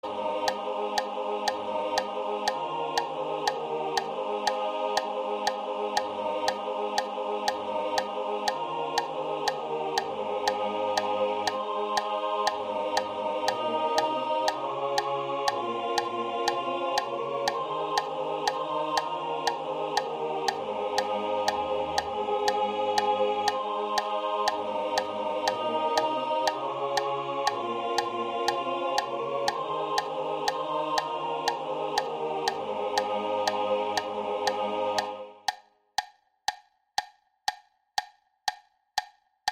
They do not feature live vocals, but are merely presented for readers to hear a sonic reference to the tonic sol-fa scores featured in the book.